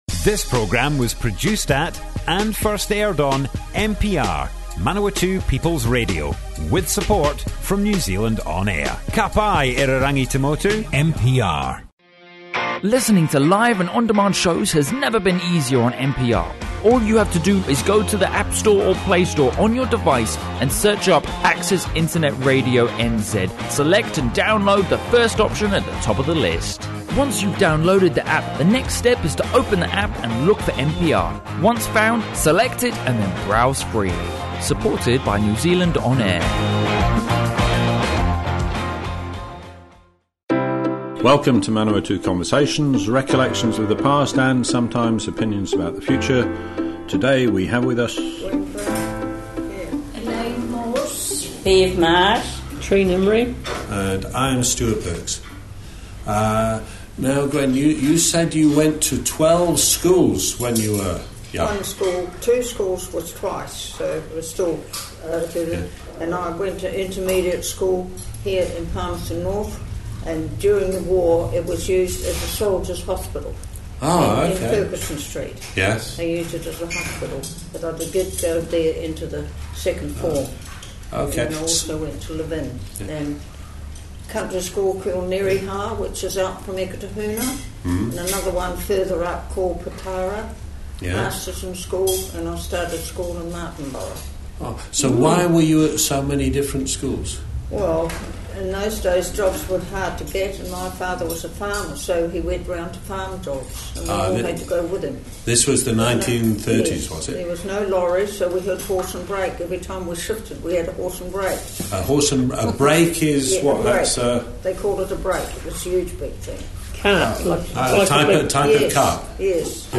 Manawatu Conversations More Info → Description Broadast on Manawatu People's Radio 25 September 2018.
oral history